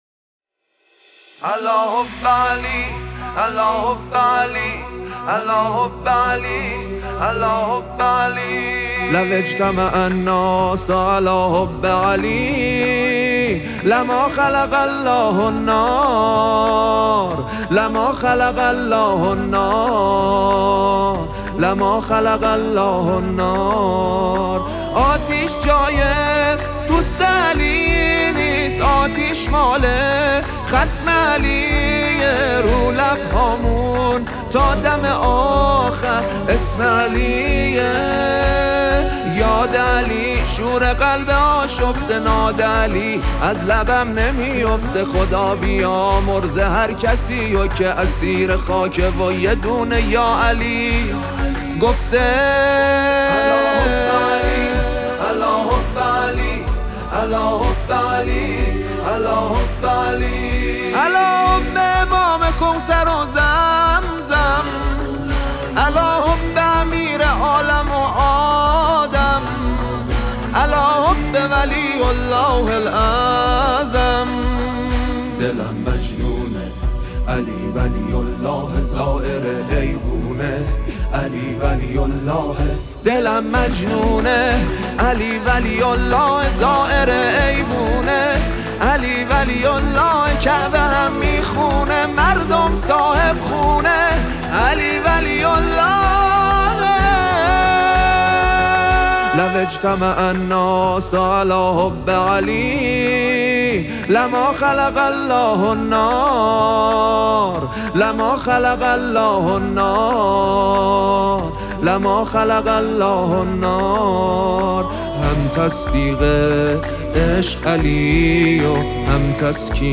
عید غدیر رو به همه شما عزیزان تبریک میگم، بهتره تو این ایام شادی ، دل و روحمون رو با یه سرود زیبا شاد کنیم !